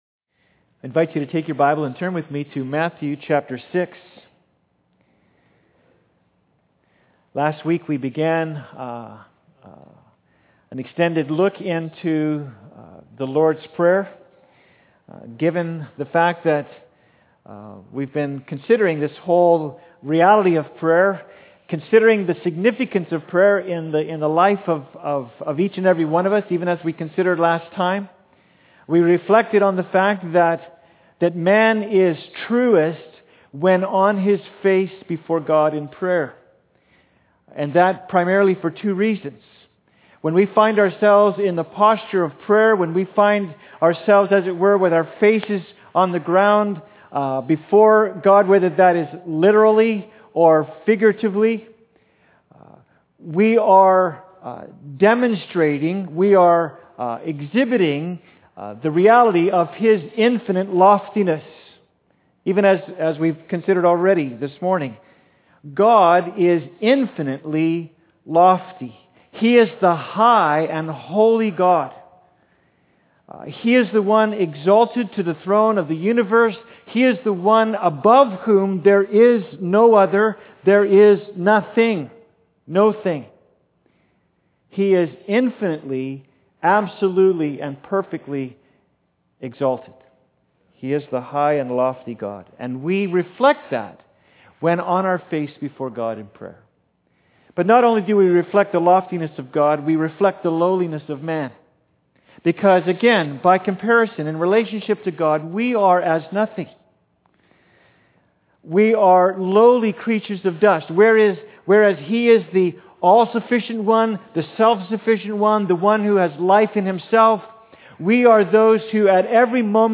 Service Type: Sunday Service
Sermon_10.19.m4a